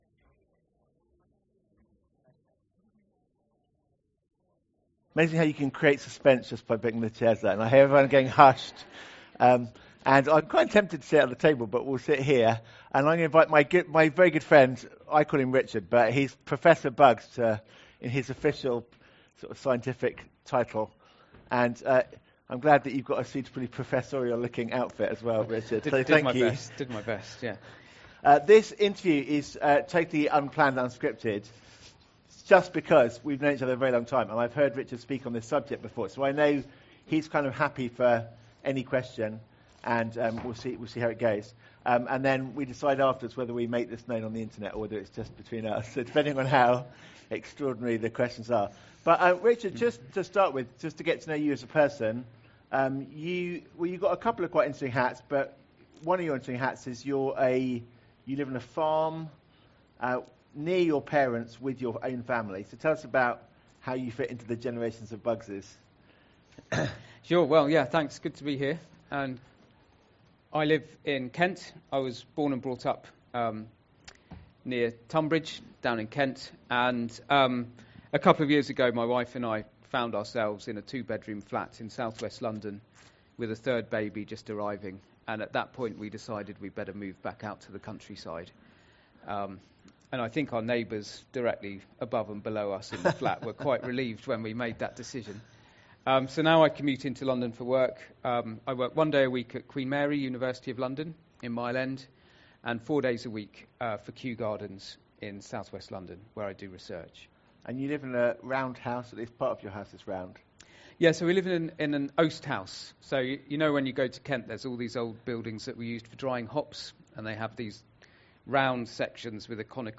Why science points us to God – Interview – Grace Church Greenwich